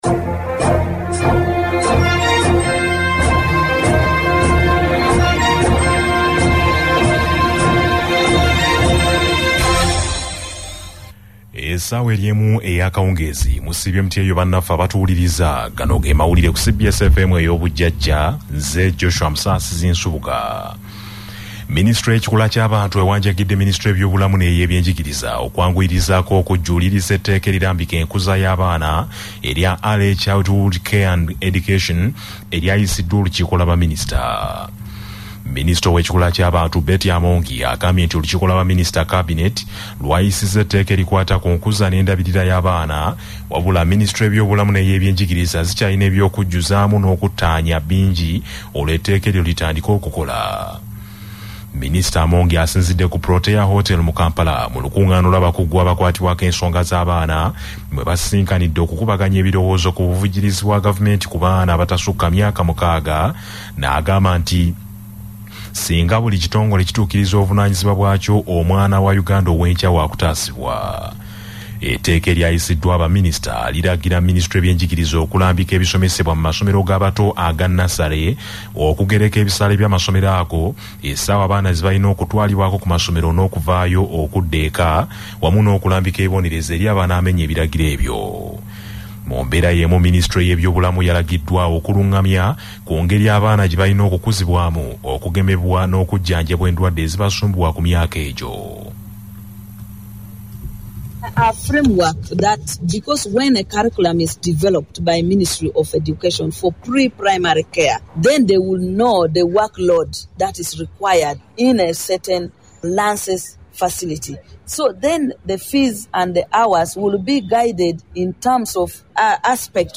Audio News